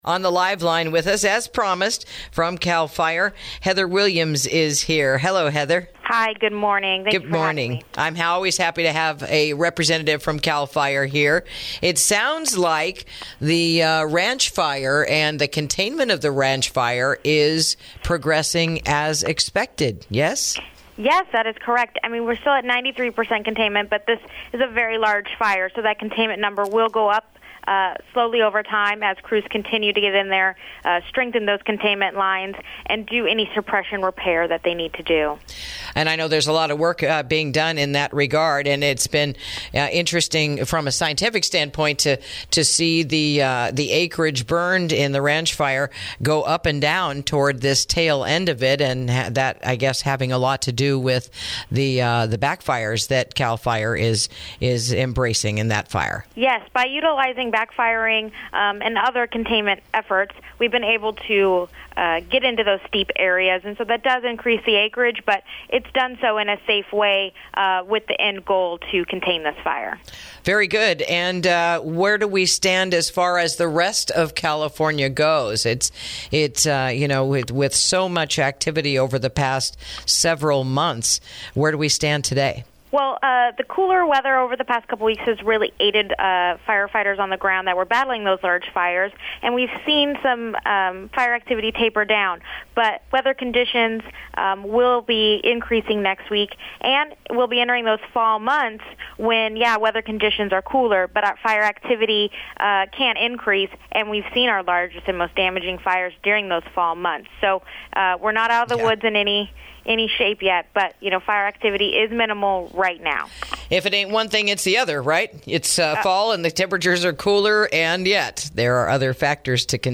INTERVIEW: Ranch Fire Approaches Full Containment as Wildfire Passes 1-Month Mark